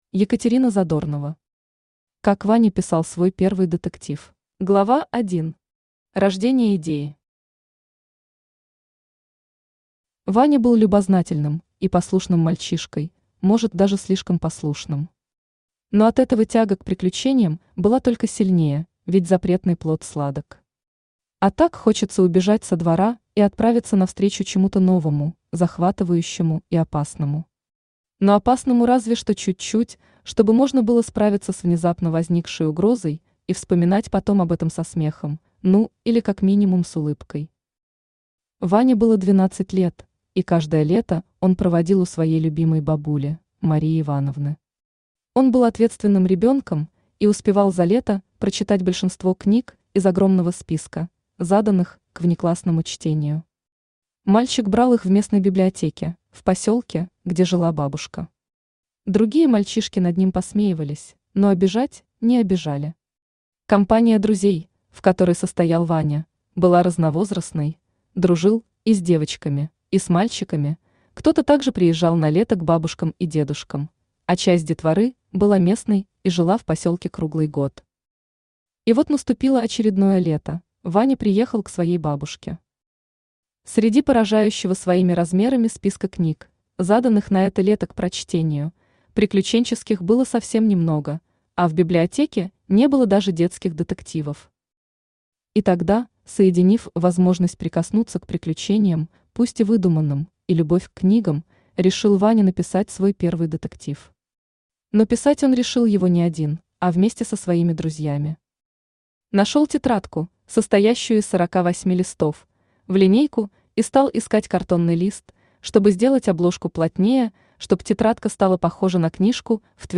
Aудиокнига Как Ваня писал свой первый детектив Автор Екатерина Александровна Задорнова Читает аудиокнигу Авточтец ЛитРес. Прослушать и бесплатно скачать фрагмент аудиокниги